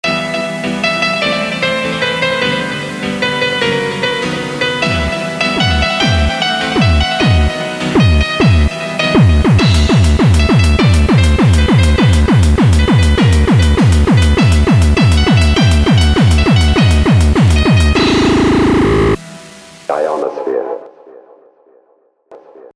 Gabber.mp3